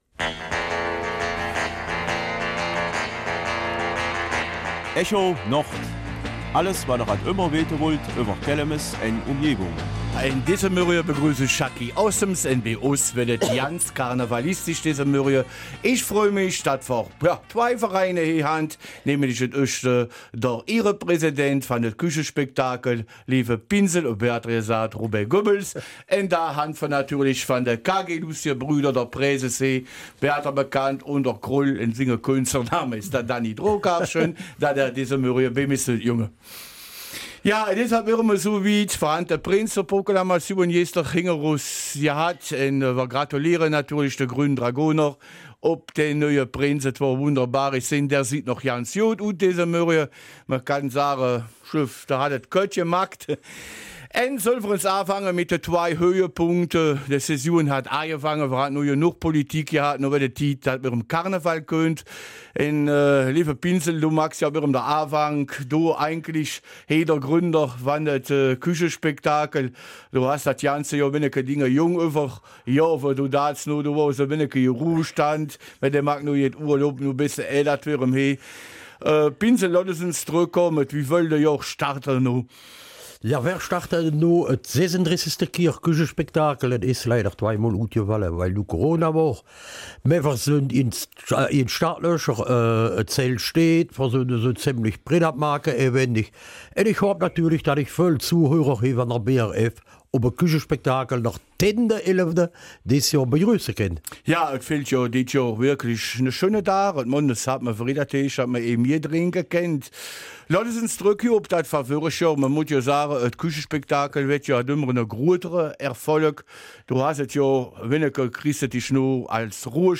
Kelmiser Mundart: Start in den Karneval